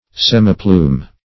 Search Result for " semiplume" : The Collaborative International Dictionary of English v.0.48: Semiplume \Sem"i*plume`\ (s[e^]m`[i^]*pl[=u]m), n. (Zool.)